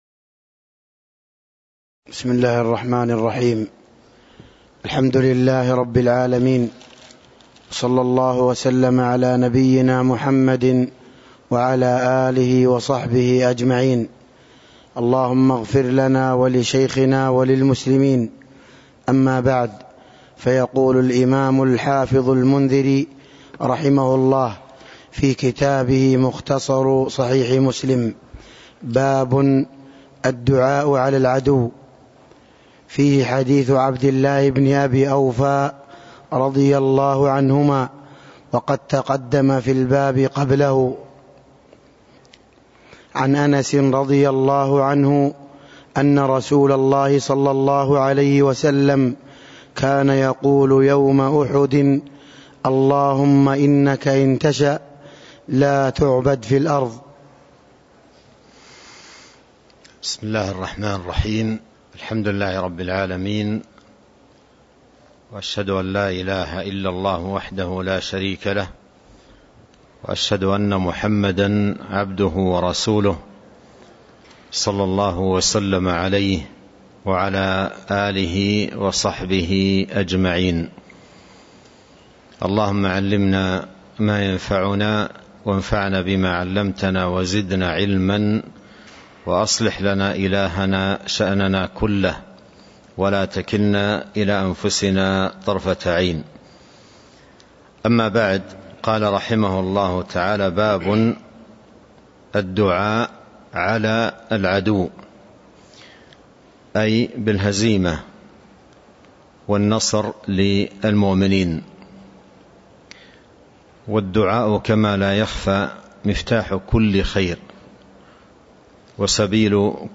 تاريخ النشر ٩ ربيع الثاني ١٤٤٣ هـ المكان: المسجد النبوي الشيخ